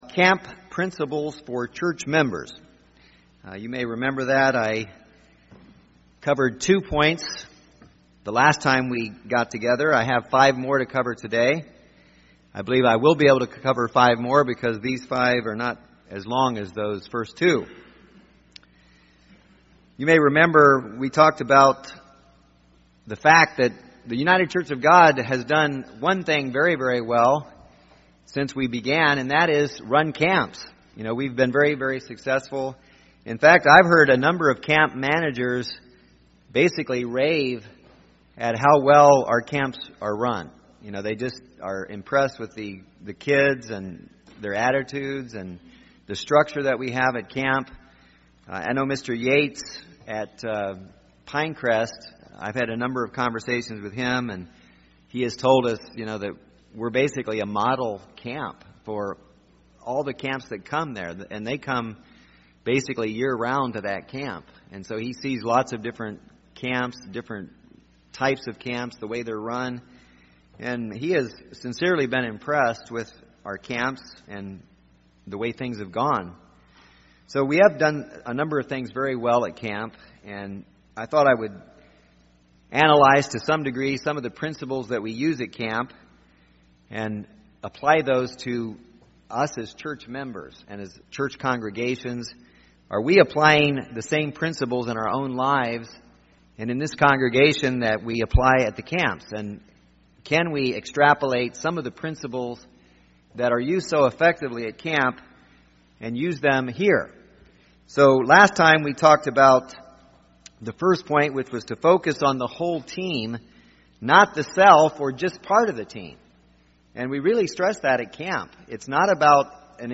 How can church members utilize these same principles to strengthen our church congregations? In this sermon seven principles are given that apply to successful camp programs and to successful church congregations.